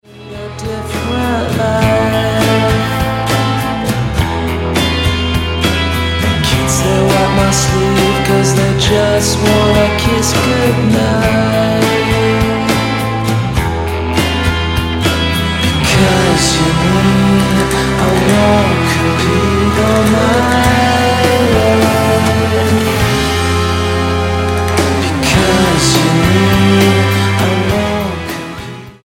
STYLE: Rock
jangly guitar riffs